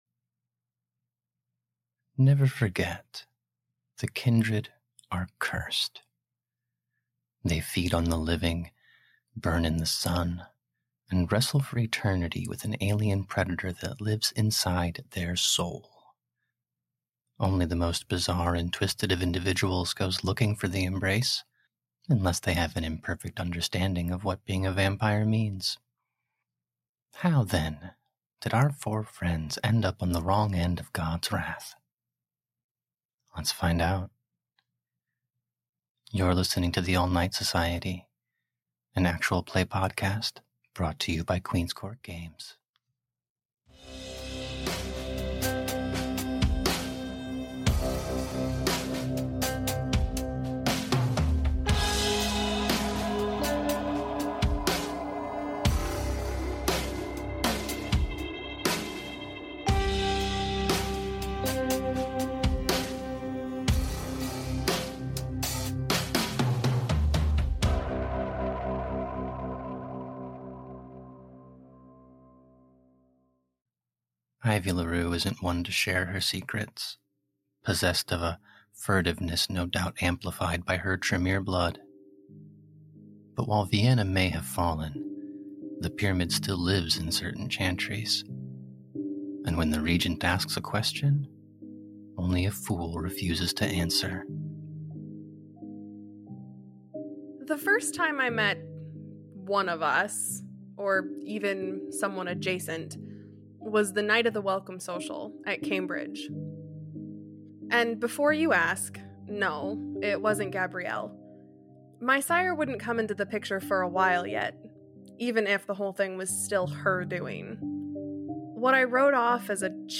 Prelude-2-the-embrace-w-music-mixdown.mp3